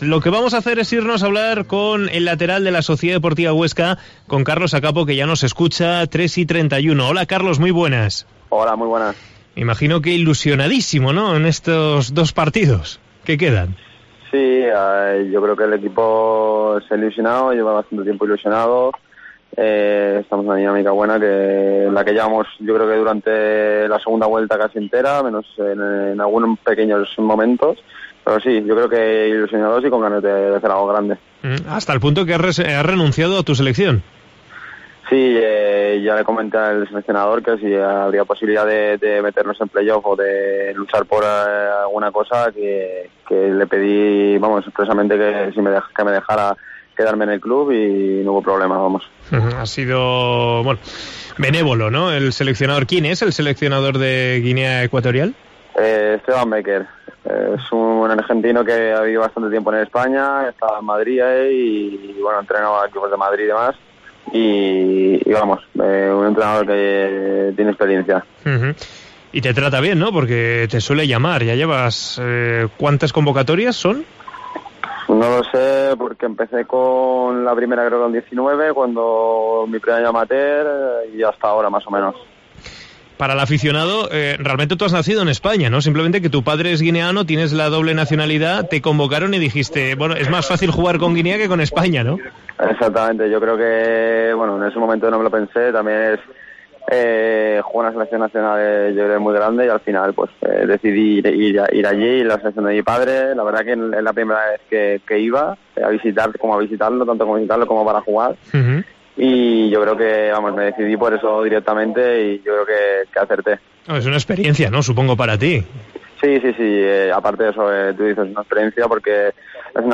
Entrevista a Carlos Akapo en COPE Huesca